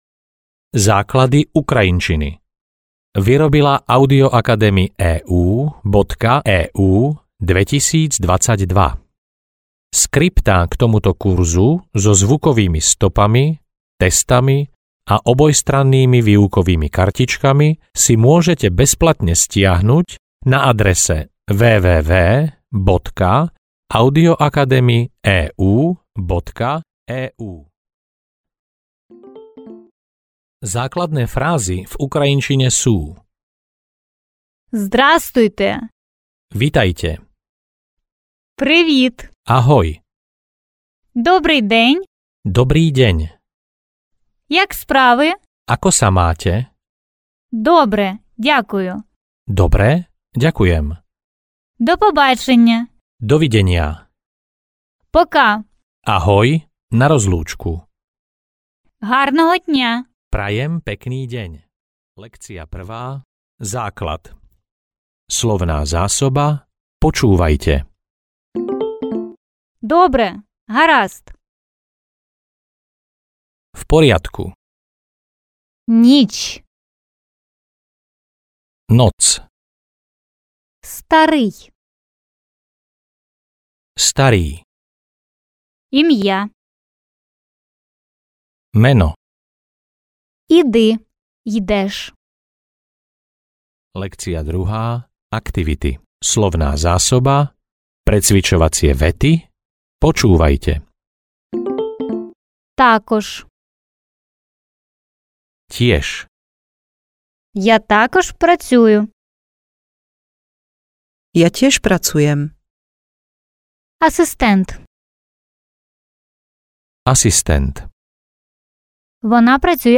Ukrajinčina – základná komunikácia A1-A2 audiokniha
Ukázka z knihy